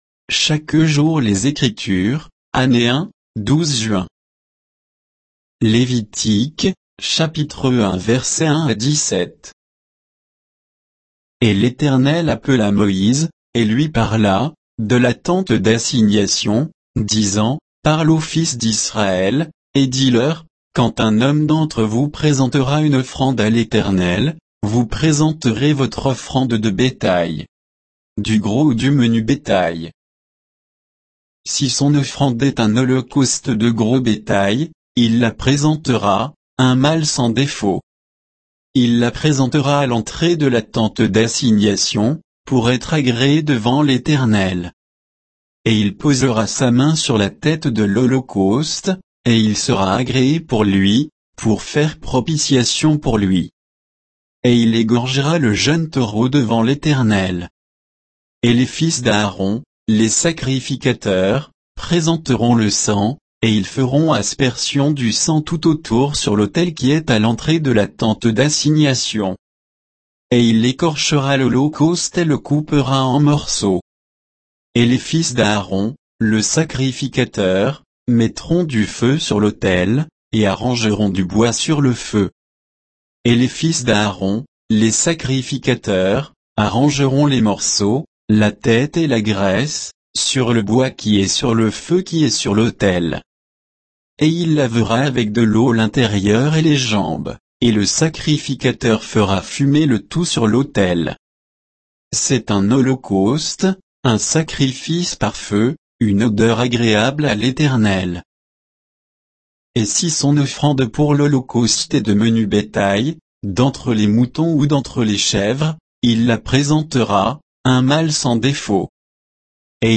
Méditation quoditienne de Chaque jour les Écritures sur Lévitique 1, 1 à 17